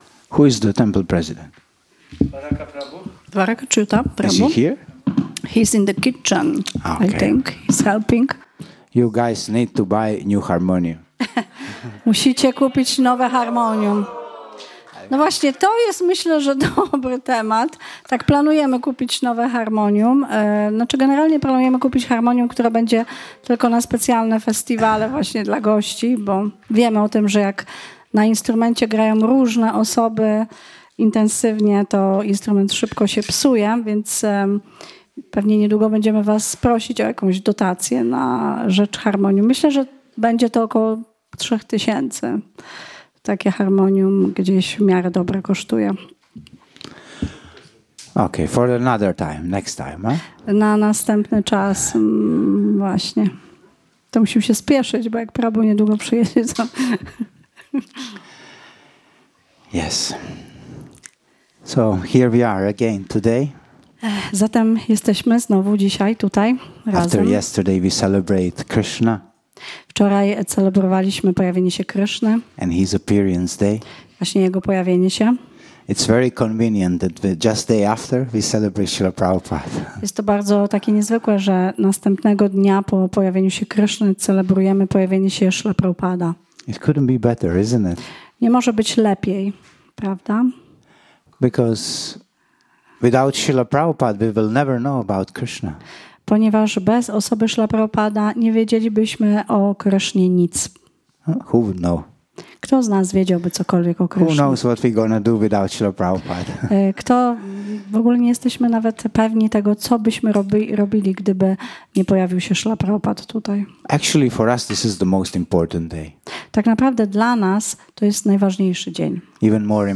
Wykład wygłoszony z okazji dnia pojawienia się Śrila Prabhupada